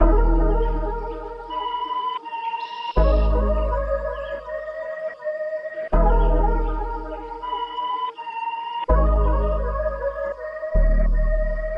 MELODY LOOPS
Codes (162 BPM – Abm)
UNISON_MELODYLOOP_Codes-162-BPM-Abm.mp3